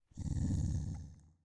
SFX_Wolf_Snarl_01.wav